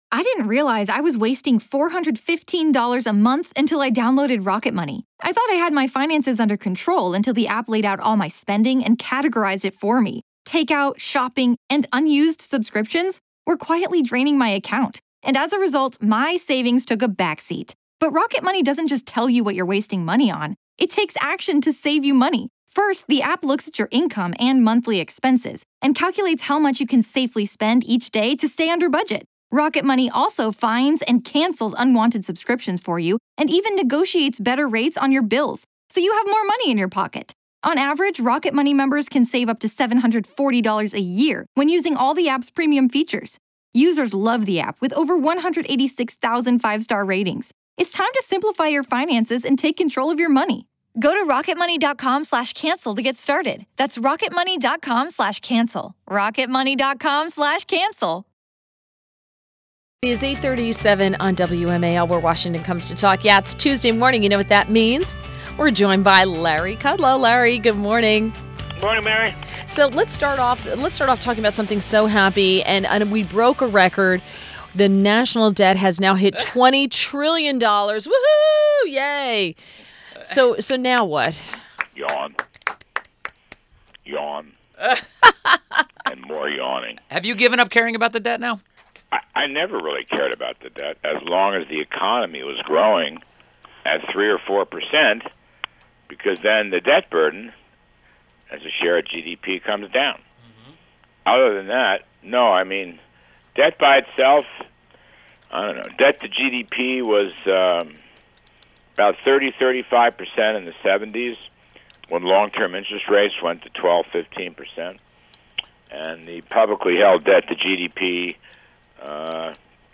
WMAL Interview - LARRY KUDLOW - 09.12.17